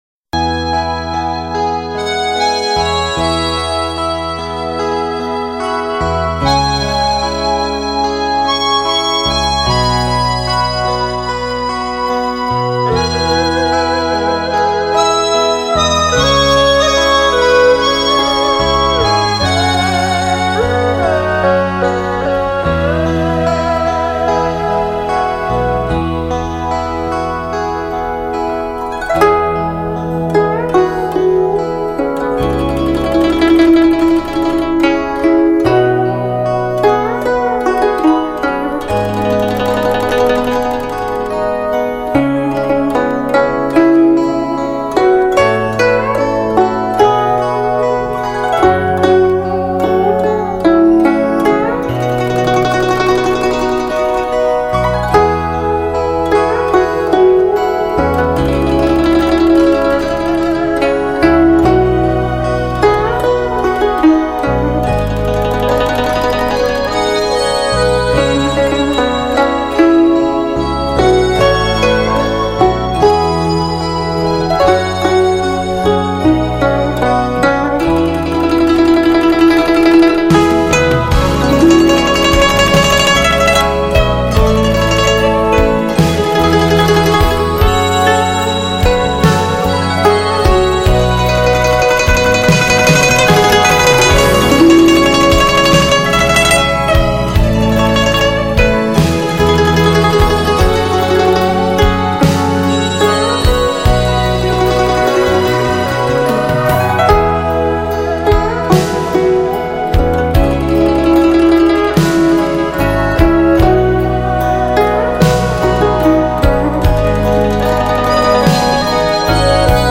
专辑简介： 一股融合新世纪电子音乐与传统民歌的现代清新潮流。
葫芦丝和古筝最现代版专辑，精彩的现代配器，原味的民族风格。
葫芦丝和古筝作为一种中国传统乐器，它独特的音色听起来极具新鲜感，带着醇厚浓郁的乡土气息。
葫芦丝
古筝